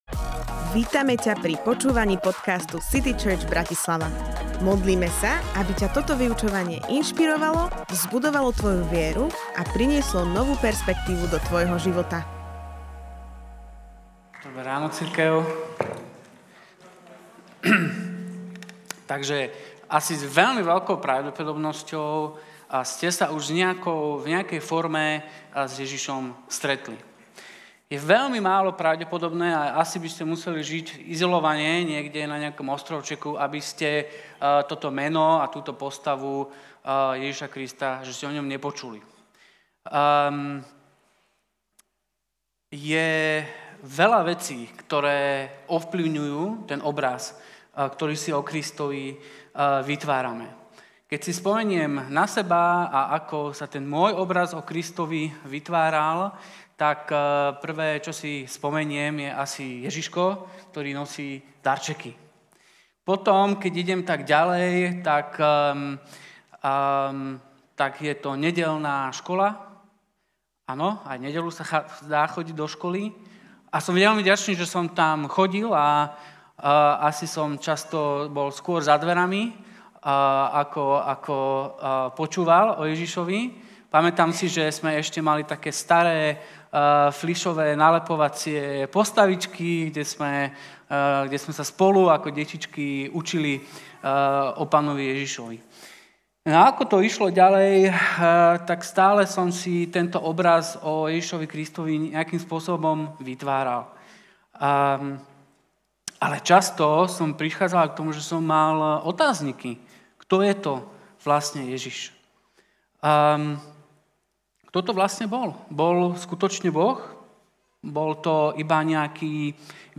Kázeň týždňa